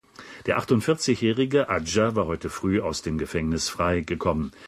In den DLF-Nachrichten, vorgelesen: